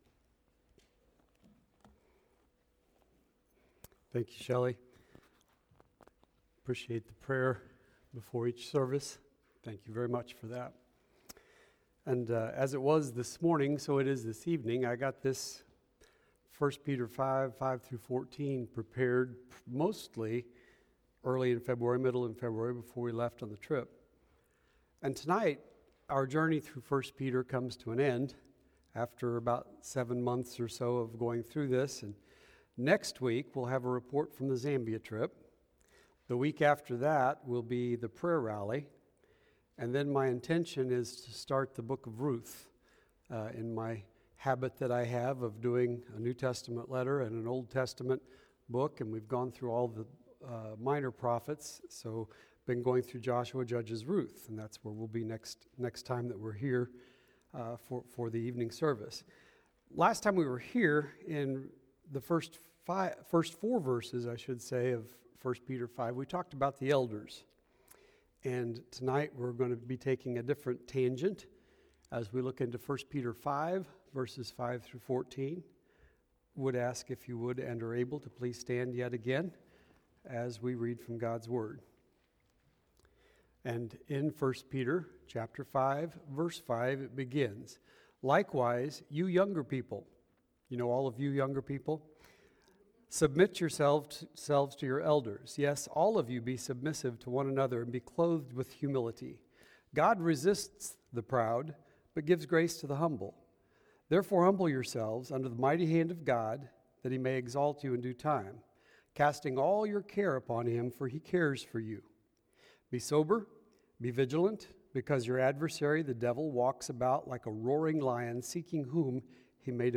Sermon Archive – Immanuel Baptist Church